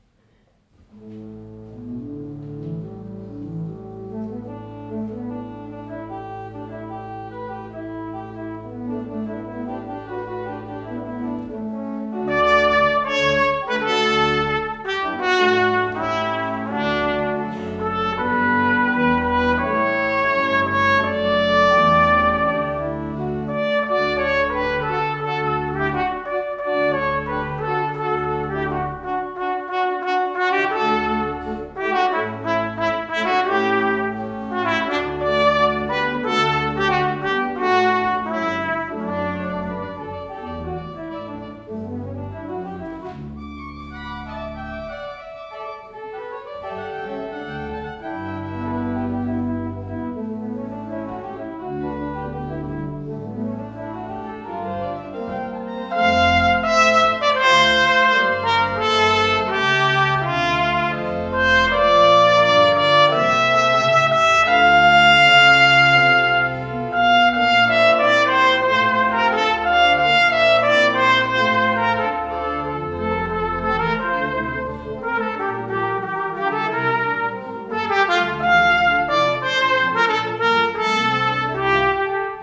concert de Noël – église Ste Croix de Lorry
Lowell Mason sur mélodie de Haendel)  –  Trompette et Orgue